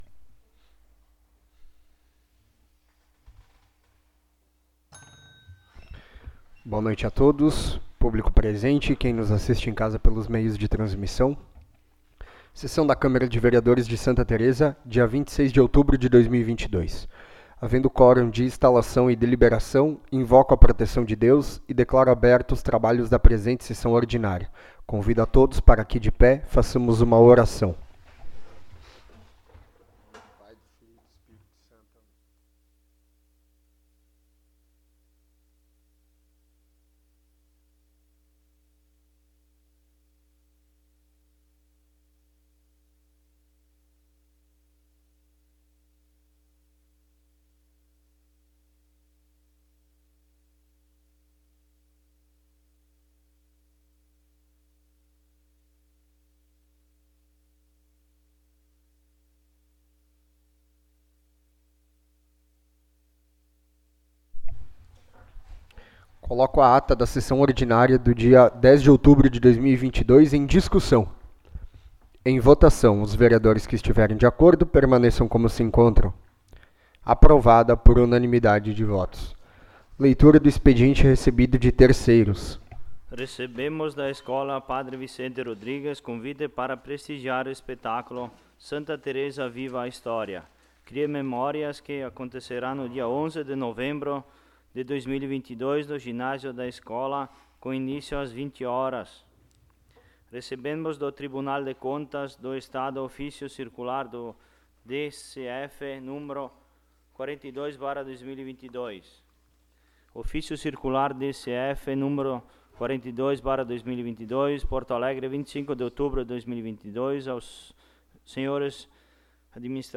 18ª Sessão Ordinária de 2022